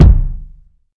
Fluffy Kick Sample C Key 61.wav
Royality free kick drum sample tuned to the C note. Loudest frequency: 130Hz
fluffy-kick-sample-c-key-61-Ywj.ogg